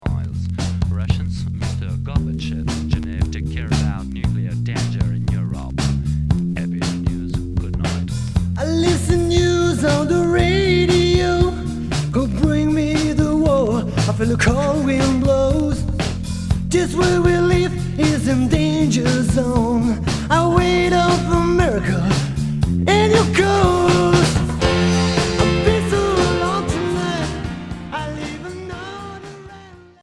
Hard mélodique